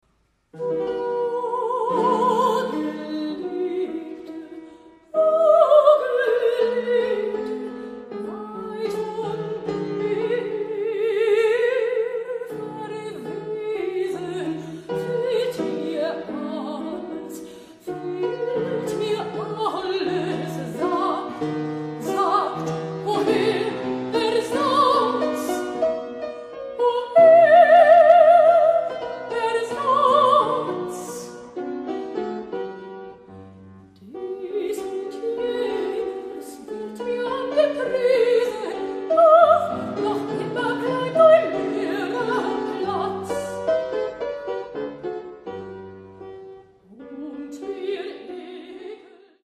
Mezzosopran
Hammerflügel